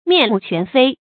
面目全非 注音： ㄇㄧㄢˋ ㄇㄨˋ ㄑㄨㄢˊ ㄈㄟ 讀音讀法： 意思解釋： 面目：樣子；非：不是。